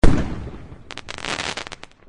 firework